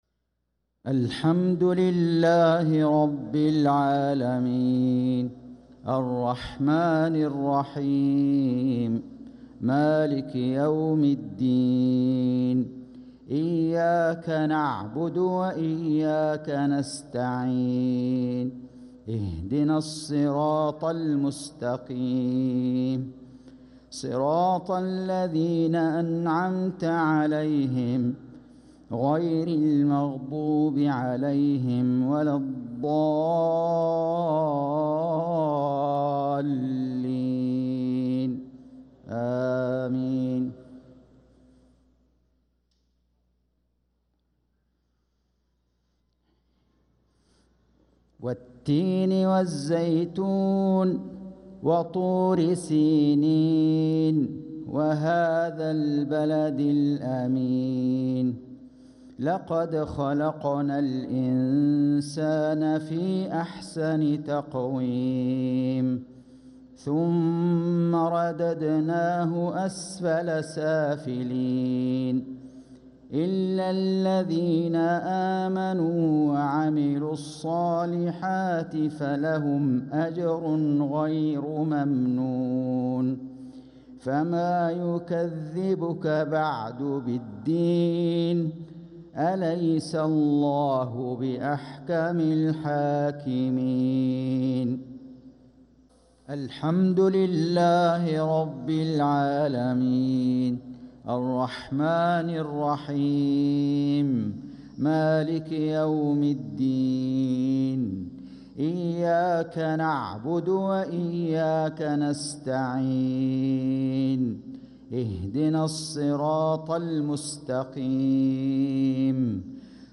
صلاة المغرب للقارئ فيصل غزاوي 22 جمادي الأول 1446 هـ
تِلَاوَات الْحَرَمَيْن .